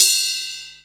Ride Cym 3.wav